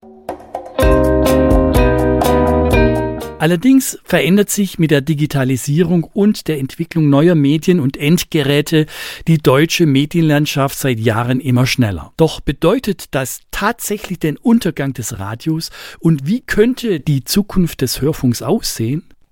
271_Teaser_Digi_2.mp3